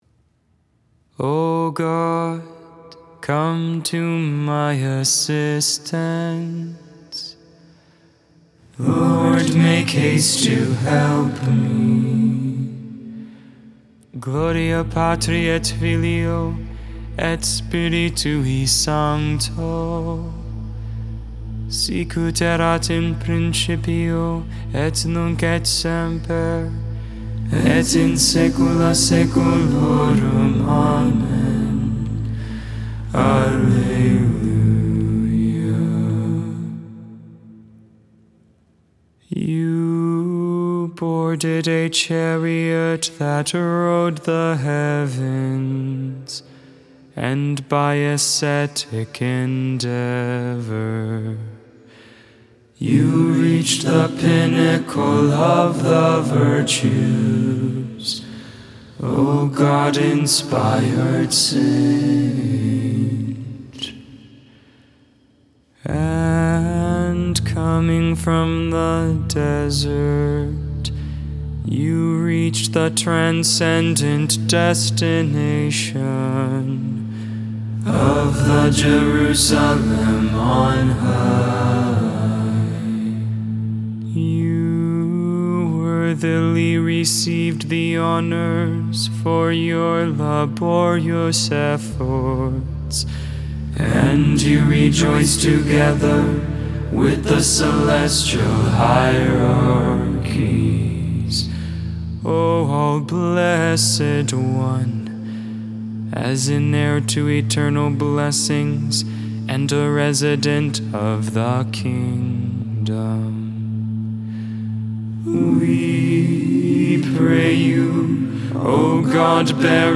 Vespers, Evening Prayer for the 2nd Monday in Ordinary Time, January 17th, 2022, Memorial of St. Anthony of the Desert.
Deus in Adjutorium Hymn: Doxastikon of the Praises (in Gregorian Tonus Peregrinus), from Greek Matins of St. Anthony.